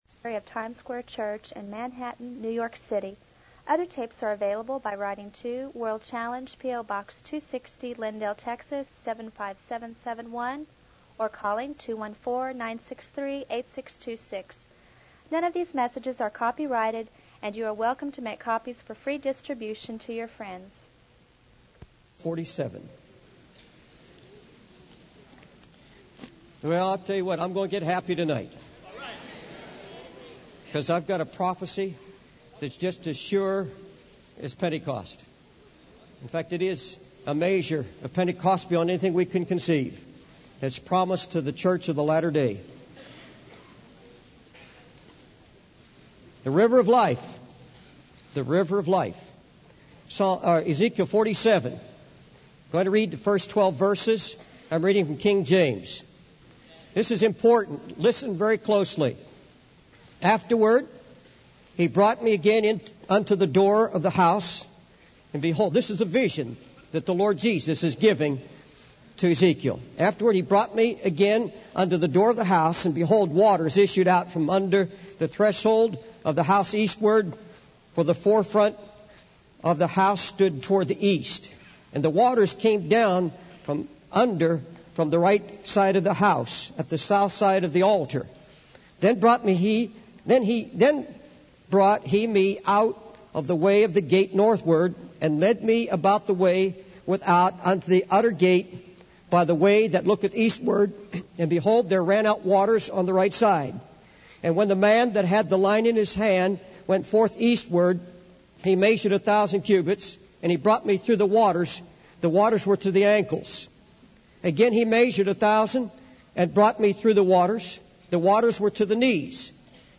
In this sermon, the preacher emphasizes the importance of guarding one's soul and staying true to Jesus. He urges the audience to get rid of anything that is unlike Jesus and to avoid falling into bitterness or rebellion.